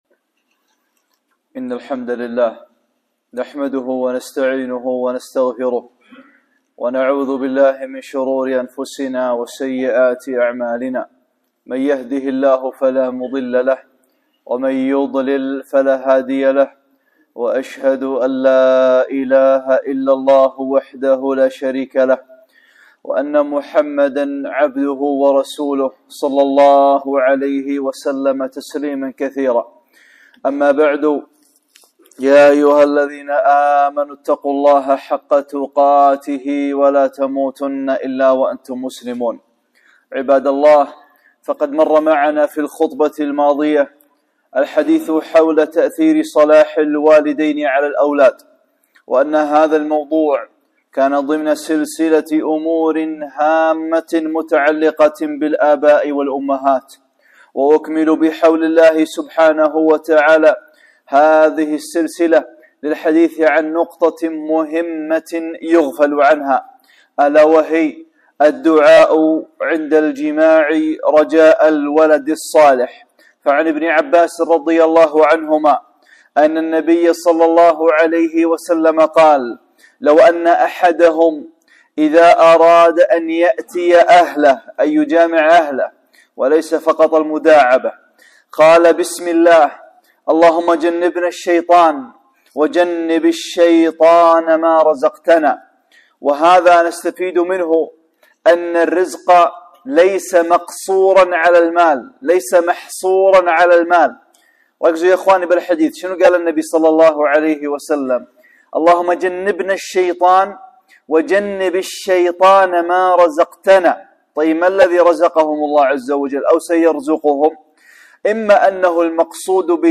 (9)خطبة - الدعاء عند الجماع | أمور هامة متعلقة بالآباء والأمهات